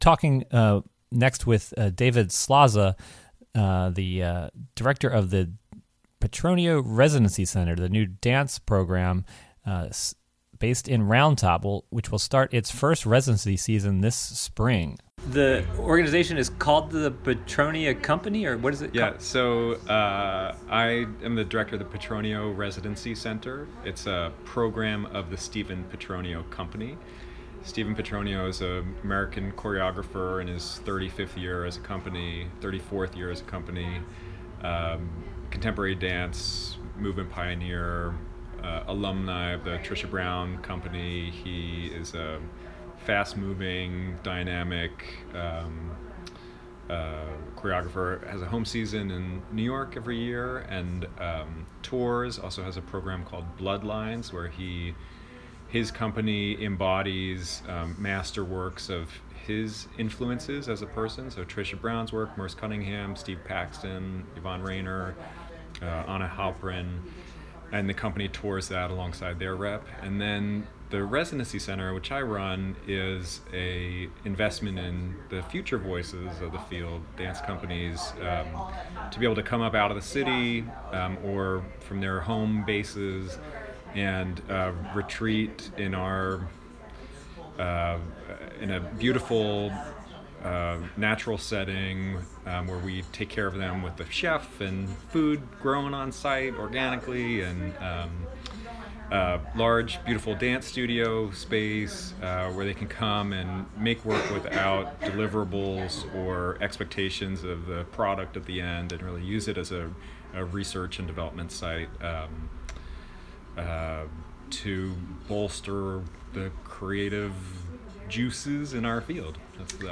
The Stephen Petronio Company is an internationally recognized dance group based in New York City, now starting a residency program in Greene County. This interview was recorded at the Prattsville Arts Center.